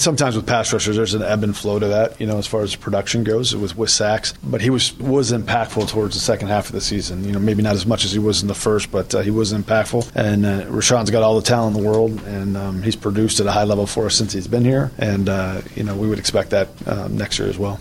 Gutekunst talks at NFL Scouting Combine: Packers GM Brian Gutekunst met with the media yesterday at the NFL Scouting Combine.